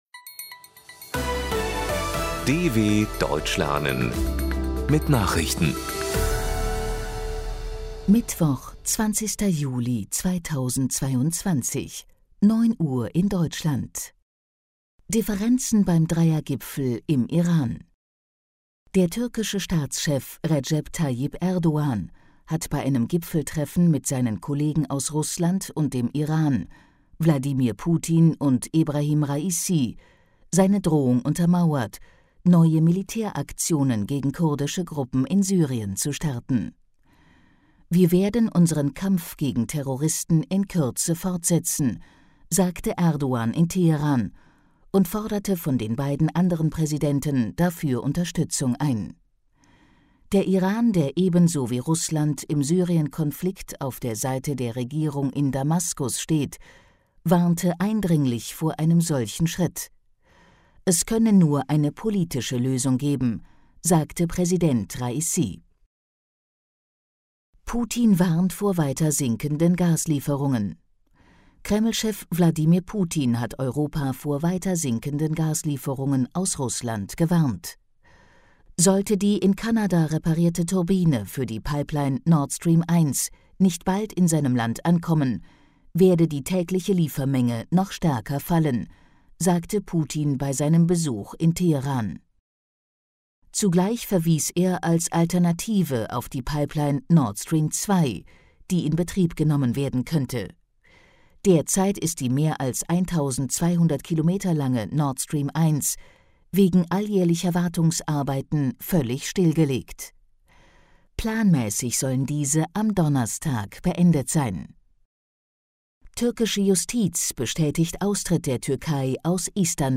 20.07.2022 – Langsam gesprochene Nachrichten
Trainiere dein Hörverstehen mit den Nachrichten der Deutschen Welle von Mittwoch – als Text und als verständlich gesprochene Audio-Datei.